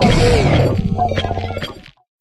Cri de Paume-de-Fer dans Pokémon HOME.